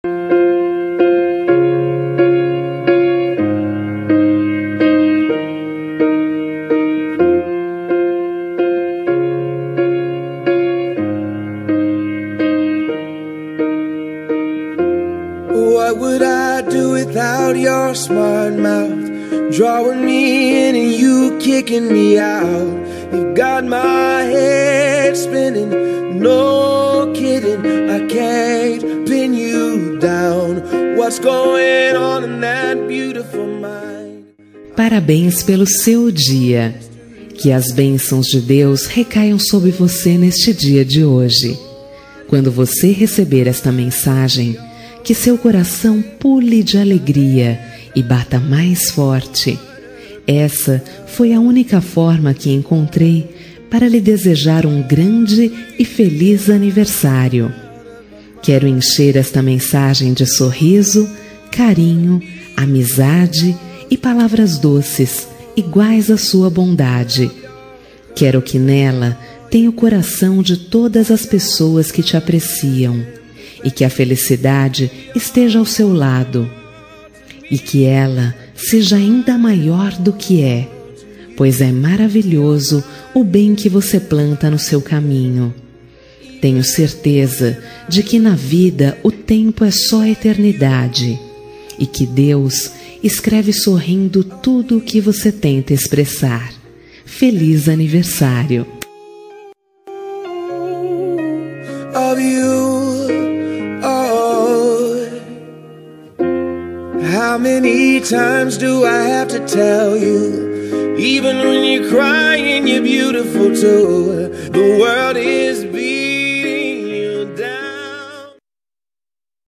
Telemensagem de Aniversário de Pessoa Especial – Voz Feminina – Cód: 4187